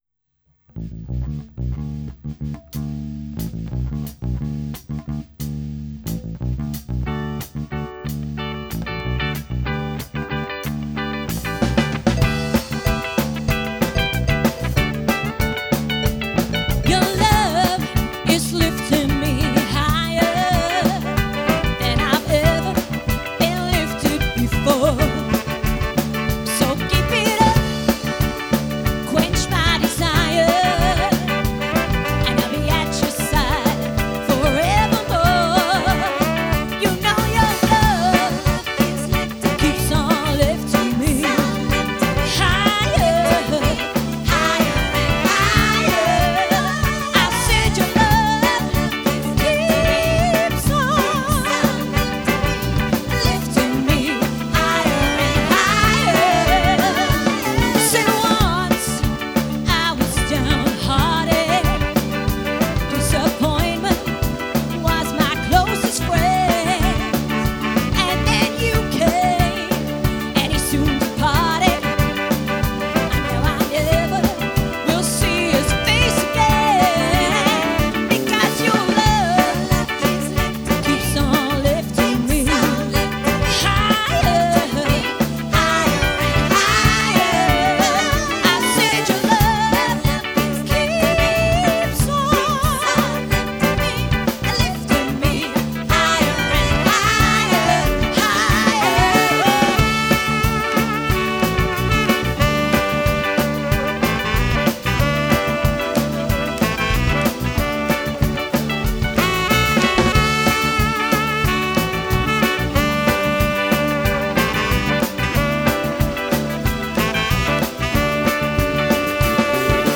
Live promo video shoot recording 12th January 2020